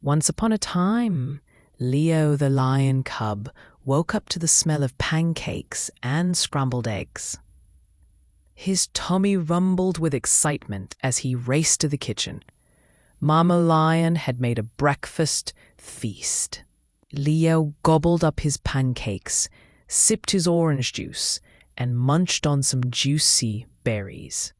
chat_completions_tts.mp3